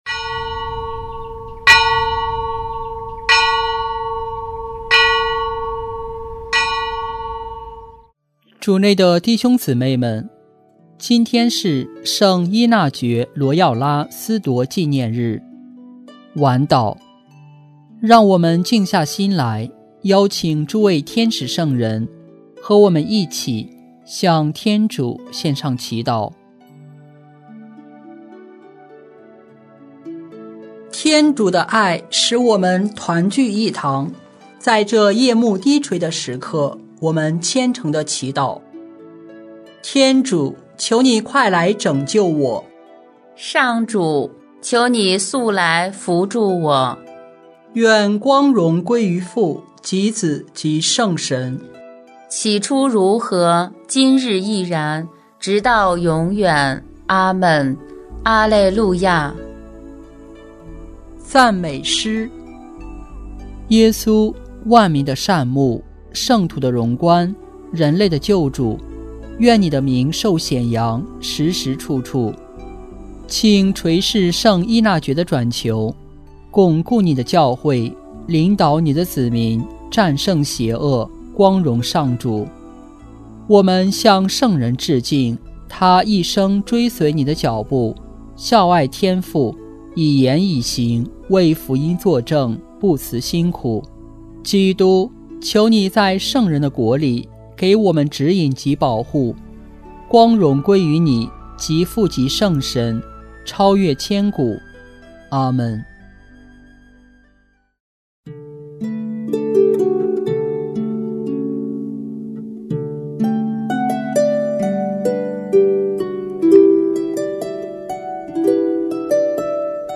【每日礼赞】|7月31日圣依纳爵罗耀拉纪念日晚祷（第一周周四）